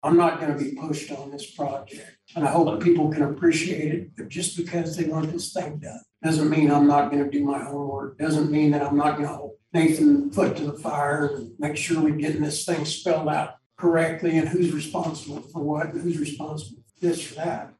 Commissioner Pat Weixelman says there’s no reason the county should be pressured into a quick decision.